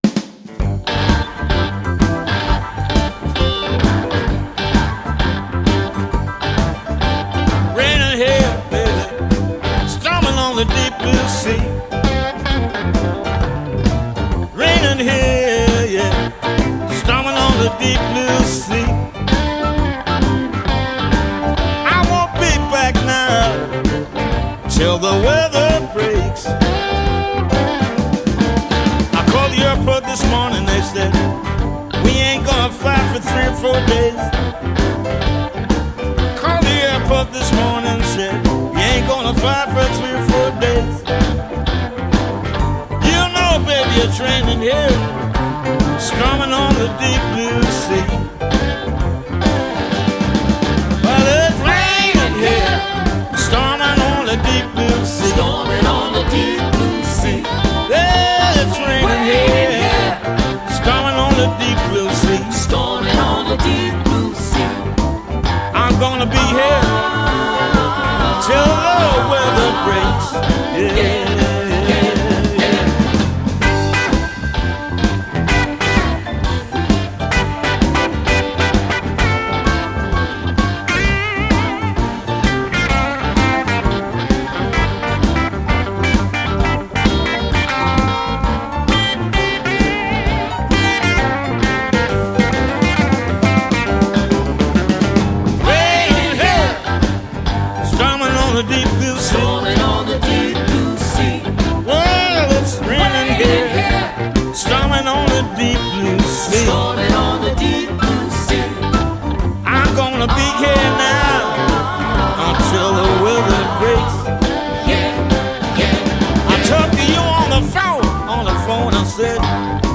It is textured.